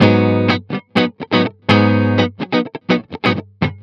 06 GuitarFunky Loop G.wav